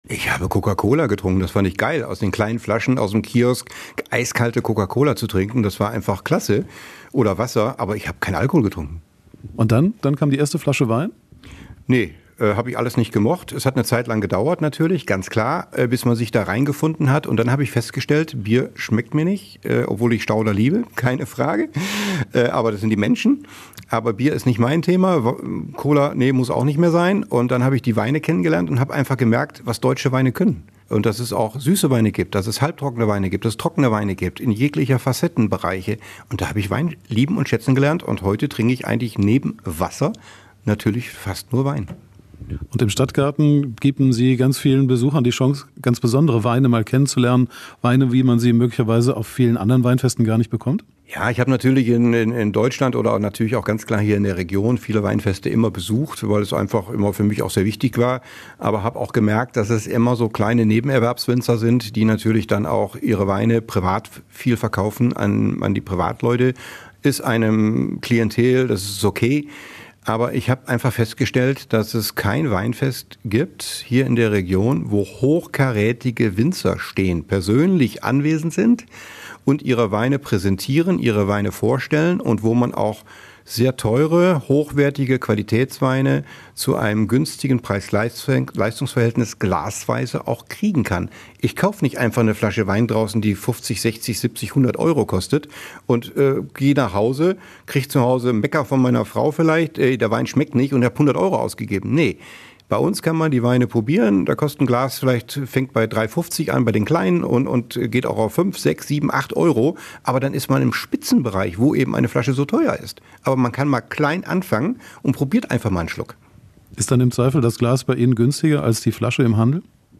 Wir haben den Veranstalter im Weinkeller besucht.